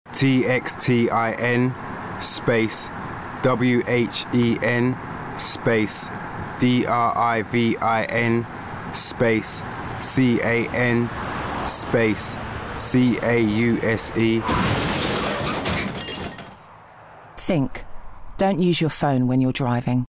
LISTEN: Texting and driving is a dangerous mix, listen to the Texting radio ad.